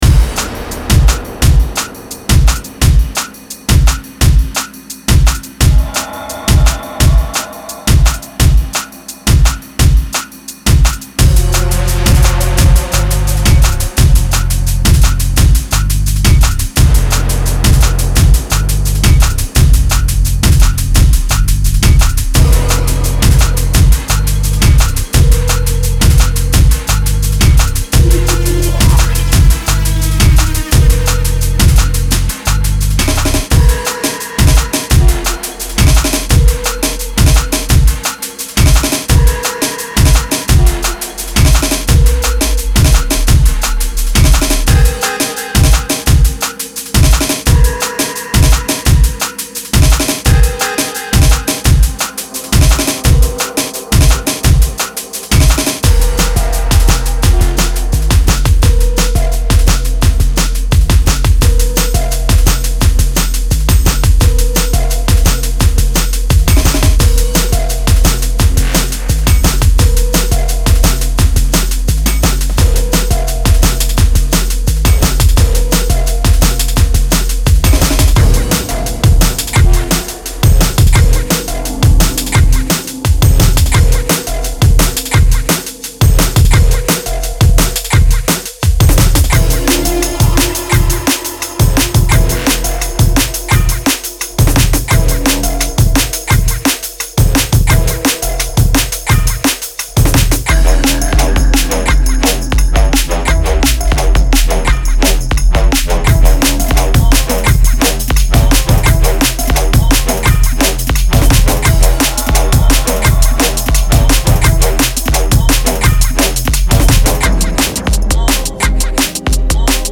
Genre:Drum and Bass
うねるベースライン、鋭いドラム、空間的なパッド
デモサウンドはコチラ↓